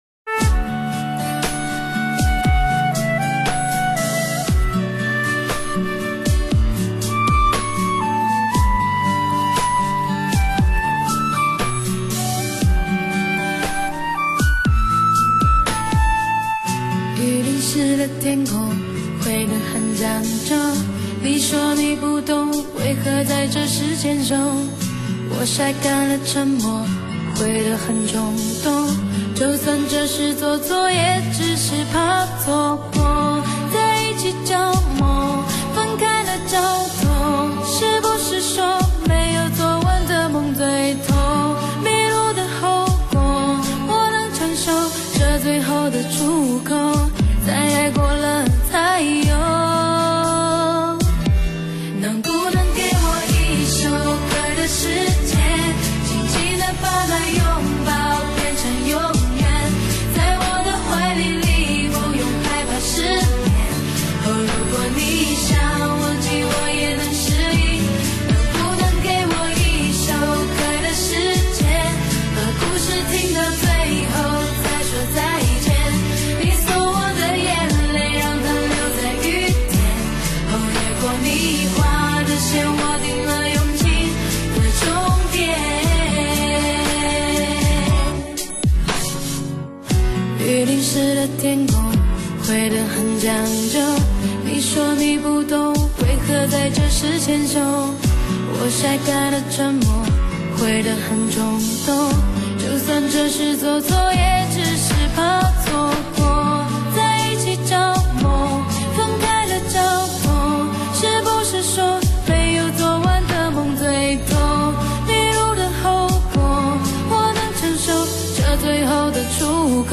汽车HI-FI音乐发烧碟
极具穿透力的嗓音